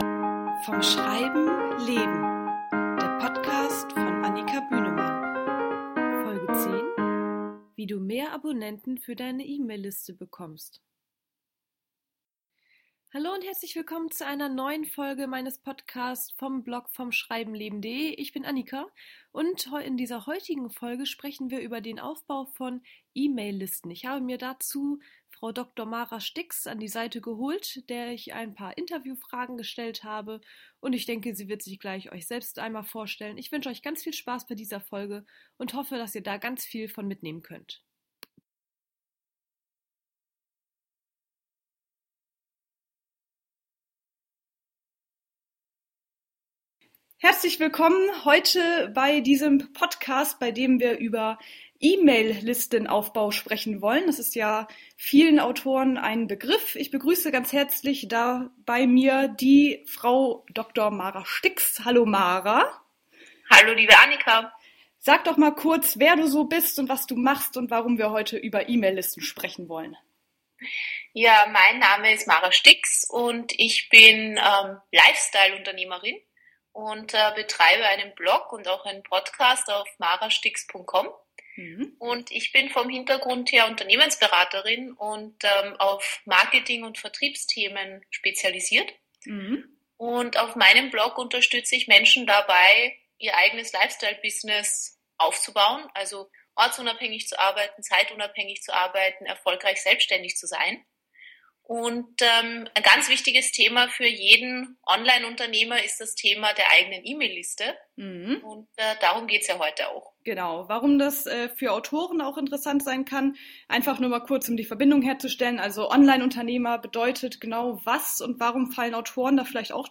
Da sie selbst überaus erfolgreich im Aufbau von E-Mail-Listen ist, habe ich mir sie geschnappt und mit ihr ein Interview zu diesem Thema geführt.